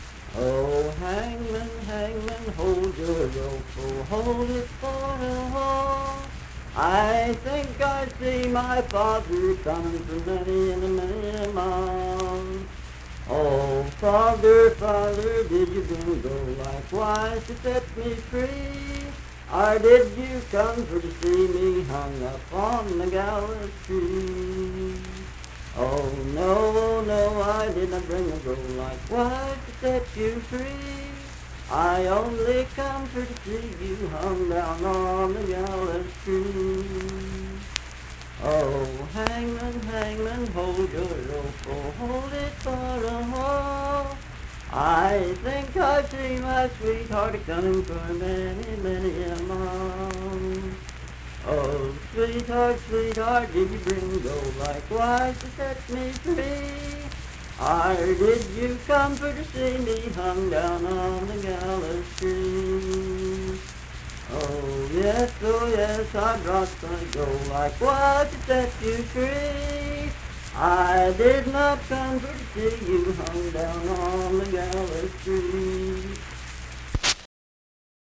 Unaccompanied vocal music
Verse-refrain 6(4w/R).
Voice (sung)